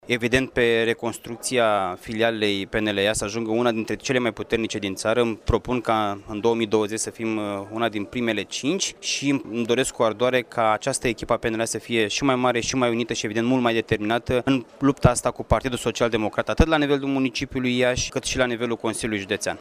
Costel Alexe a menţionat priorităţile sale în noua funcţie: